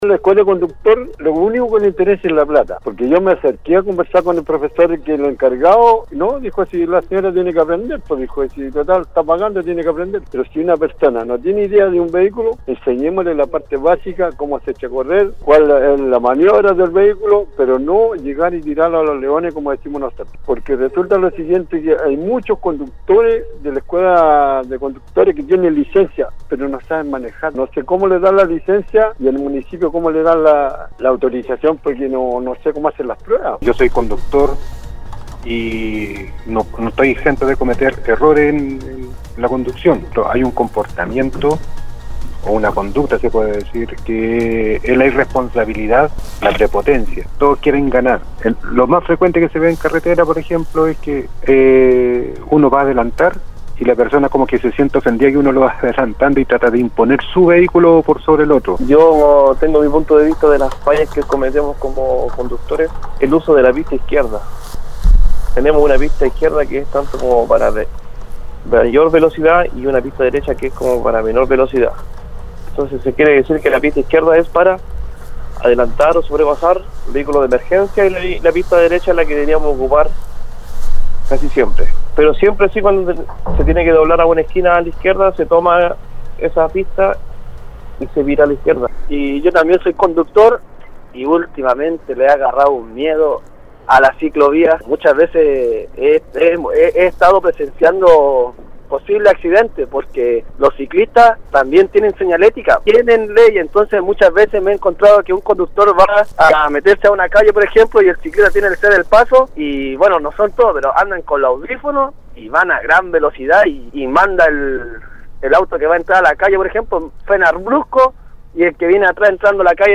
La mañana de este martes, los auditores participaron en el foro del programa Al Día donde se tocó el tema de los errores más comunes cunado se realizan labores de conducción en la Región de Atacama, por lo que se recibieron llamadas y mensajes para opinar en relación a las fallas que tienen los conductores.